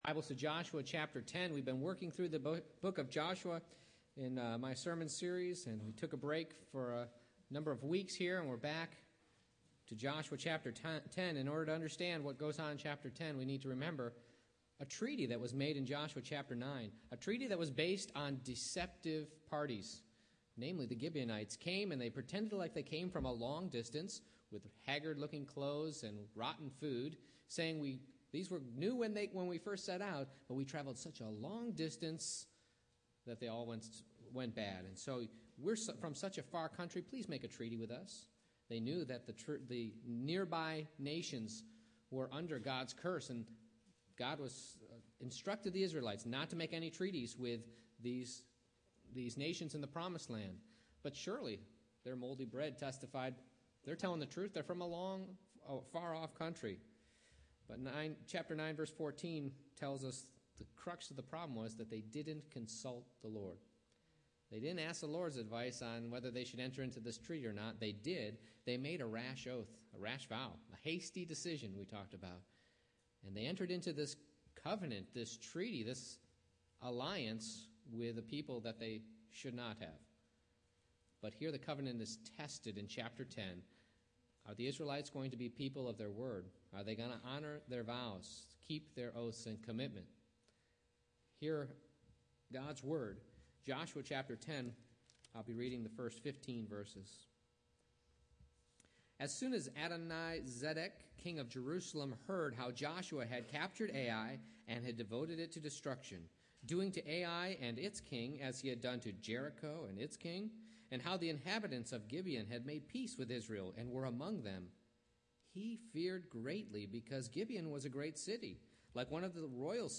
Joshua 10:1-15 Service Type: Morning Worship I. The Covenant is Tested A. The Nations Rage B. The Lord Promises II.